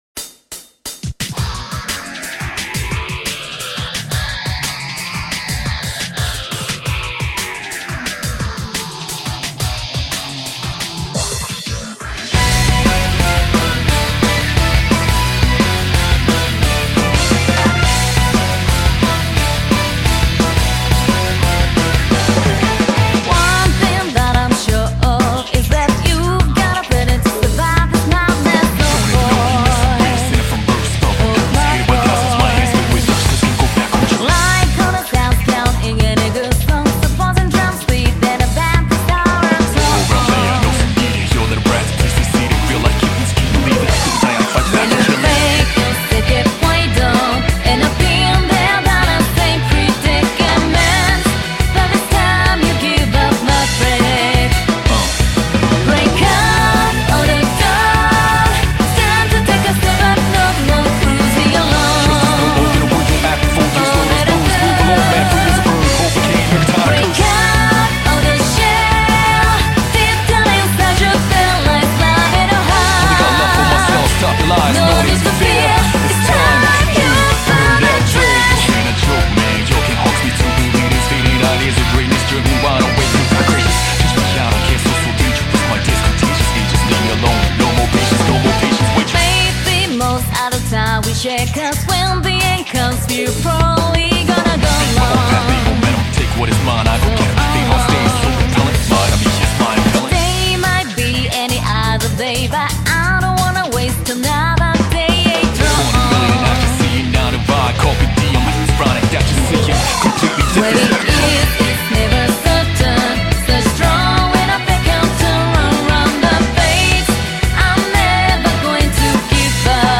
BPM87-175
Audio QualityCut From Video